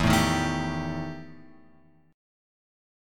EmM7bb5 chord {0 0 1 2 x 3} chord